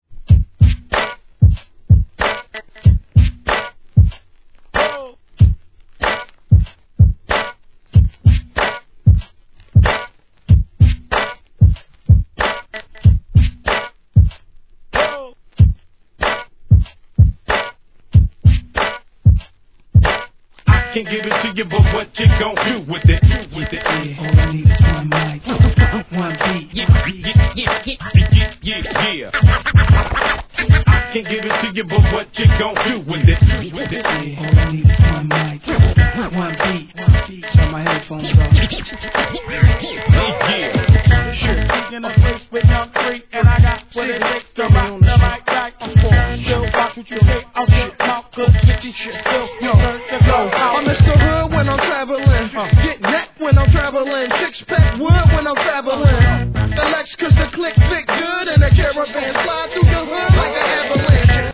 HIP HOP/R&B
DJのために繋ぎ易さも考慮されたREMIX人気シリーズ67番!!